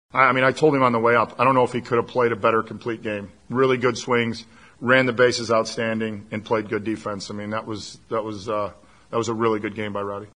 Manager Derek Shelton said Tellez was spectacular.